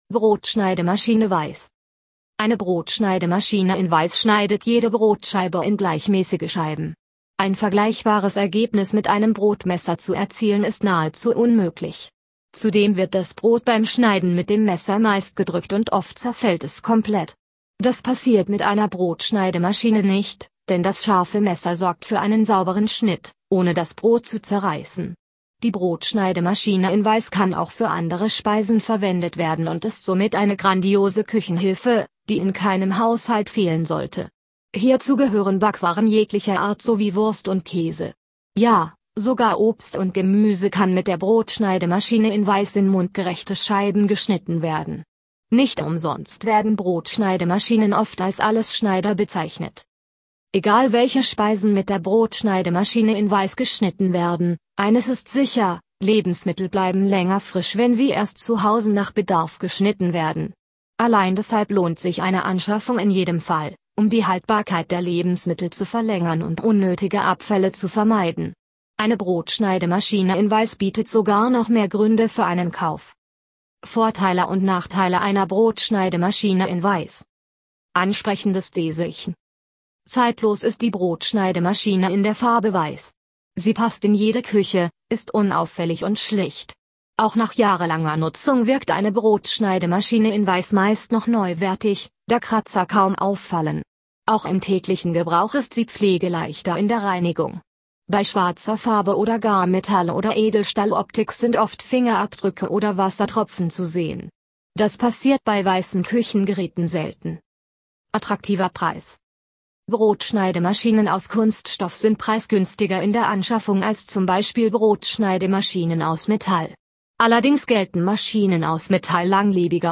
(Audio für Menschen mit Seh- oder Leseschwäche – Wir lesen Ihnen unseren Inhalt vor!)